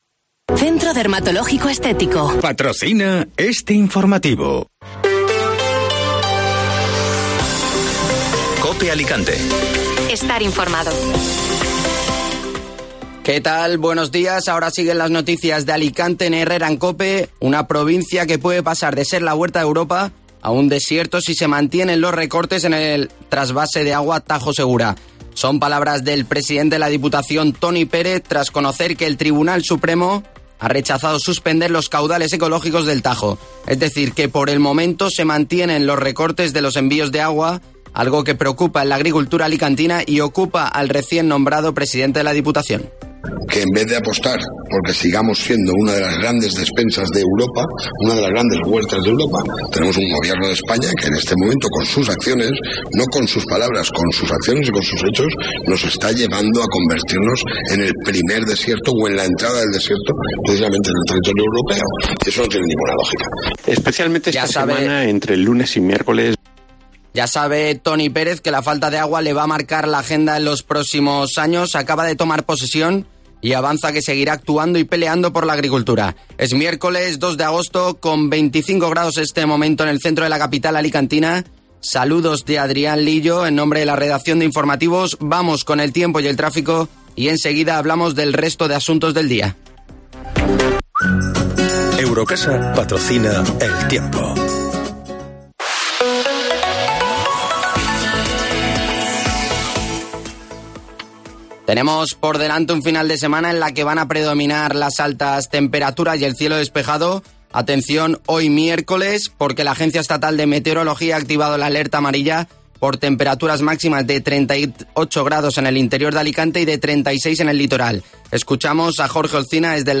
Informativo matinal (Miércoles 2 de Agosto)